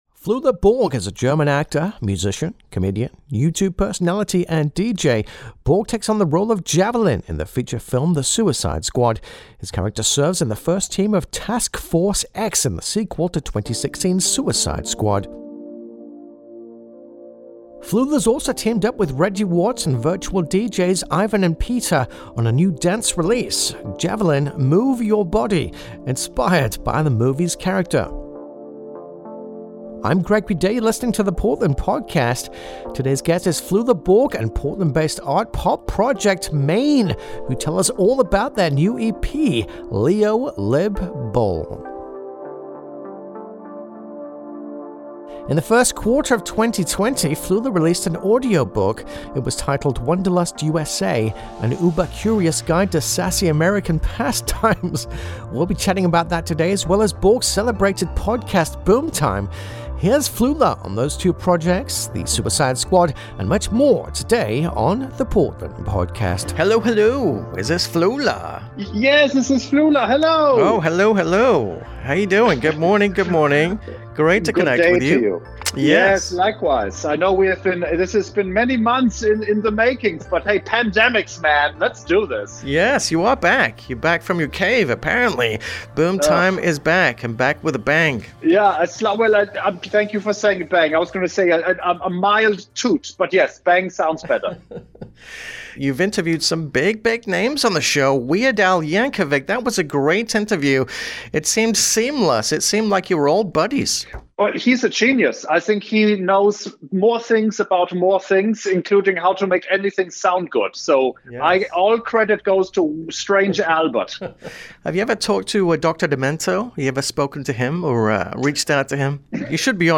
Portland-based art-pop project MANE chats with us in the second segment of today's show about their new EP 'Leo // Lib // Bull.' Music used under license.